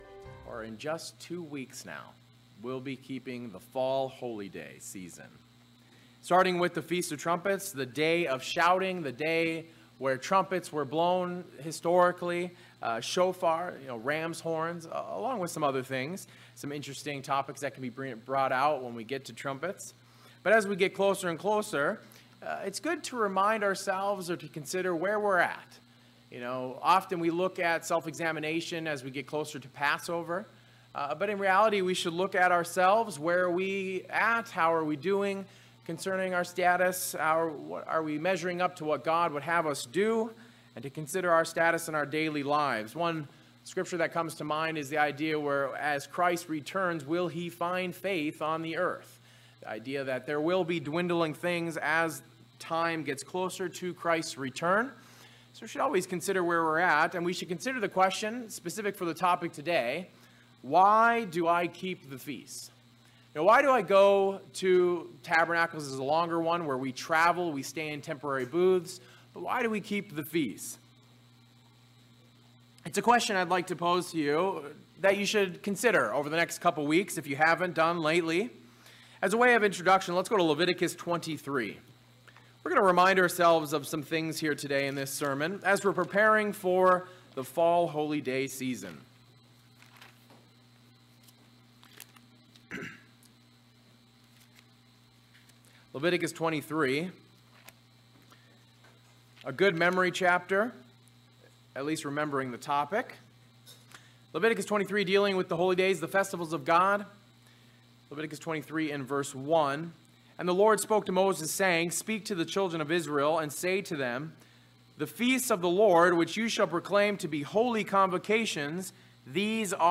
As we are preparing to keep the Fall Festivals, God expects us to prepare our hearts for this time. The sermon reviews how we can prepare our minds for keeping the Fall Festivals.